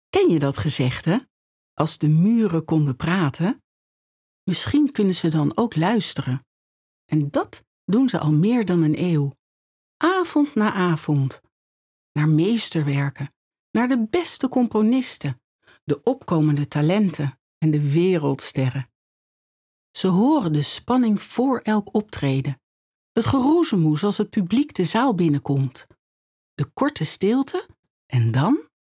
Verspielt, Vielseitig, Zuverlässig, Freundlich, Warm
Unternehmensvideo